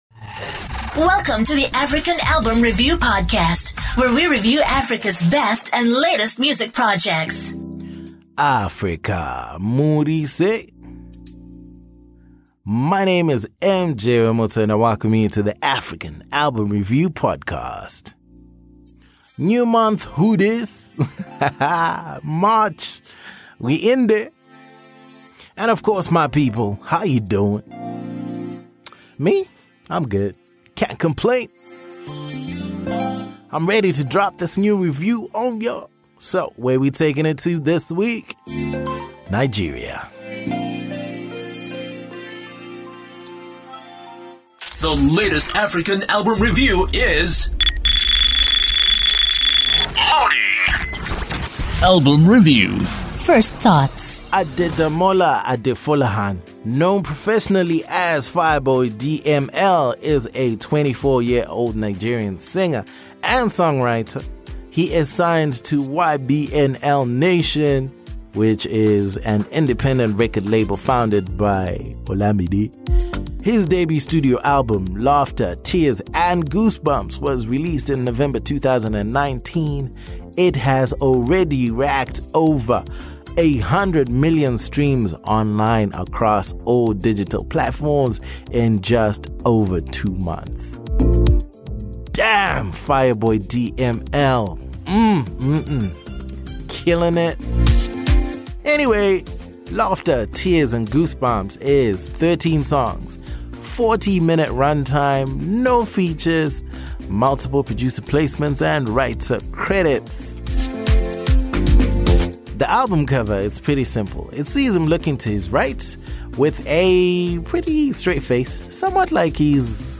He is a star and here is a review for his latest project.